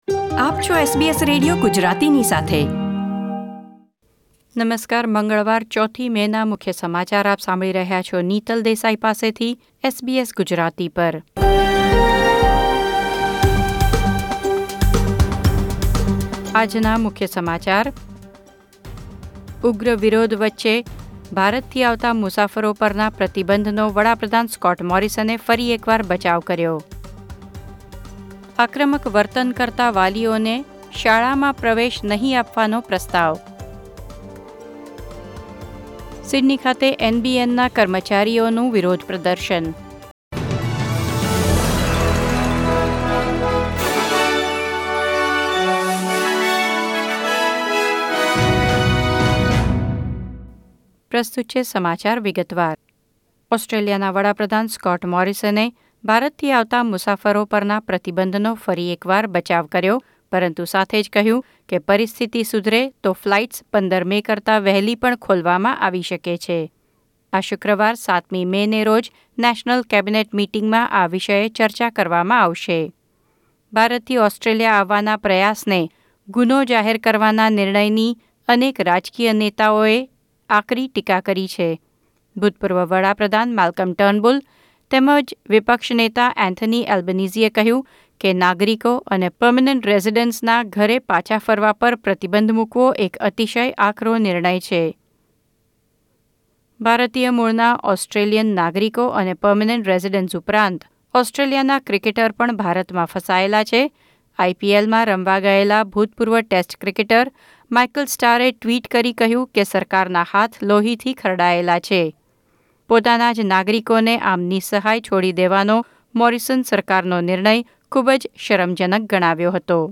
SBS Gujarati News Bulletin 4 May 2021